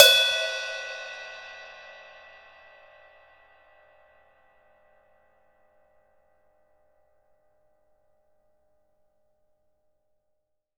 Index of /90_sSampleCDs/Sampleheads - New York City Drumworks VOL-1/Partition A/KD RIDES